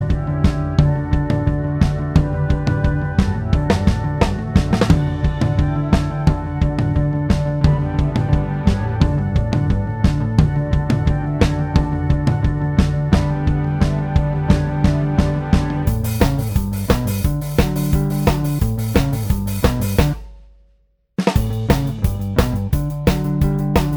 Minus Guitars Pop (2000s) 2:17 Buy £1.50